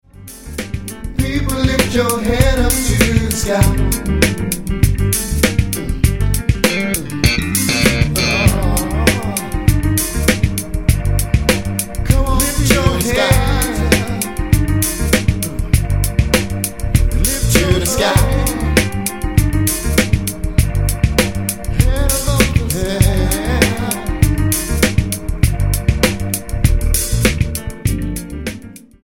R&B gospel
Style: R&B